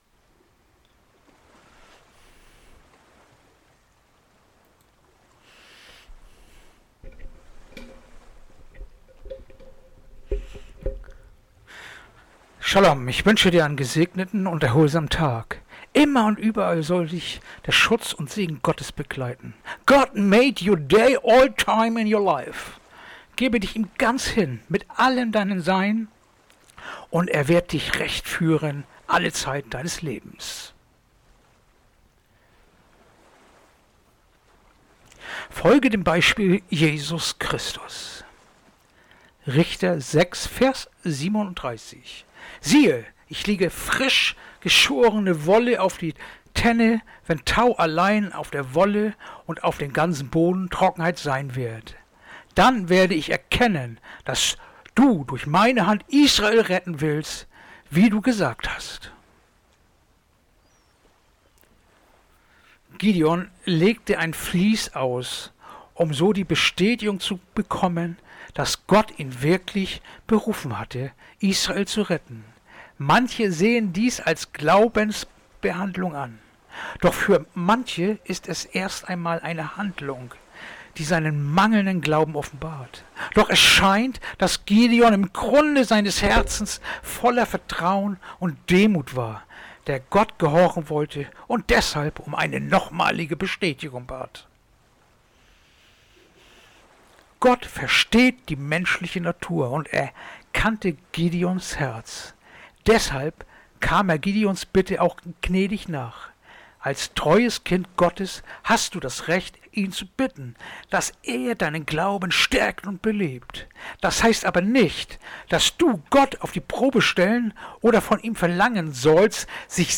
Andacht-vom-25.-Februar-Richter-6-37
Andacht-vom-25.-Februar-Richter-6-37.mp3